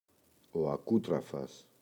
ακούτραφας, ο [aꞋkutrafas]
ακούτραφας-ο.mp3